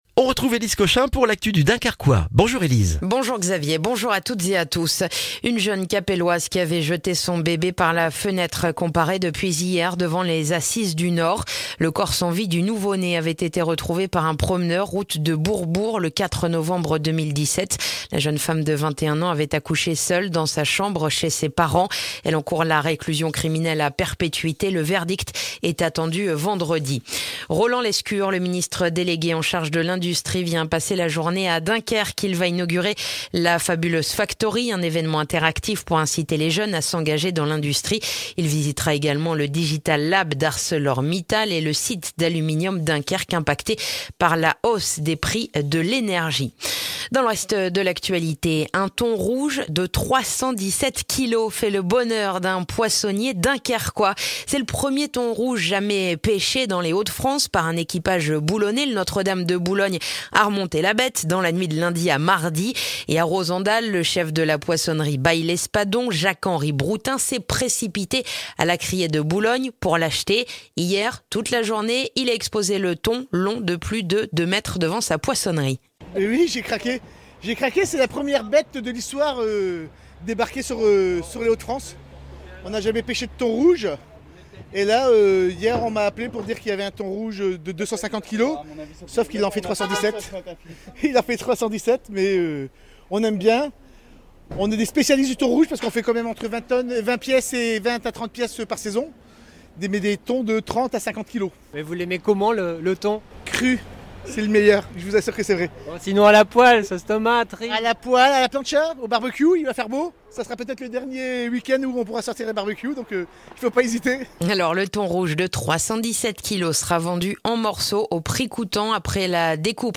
Le journal du jeudi 22 septembre dans le dunkerquois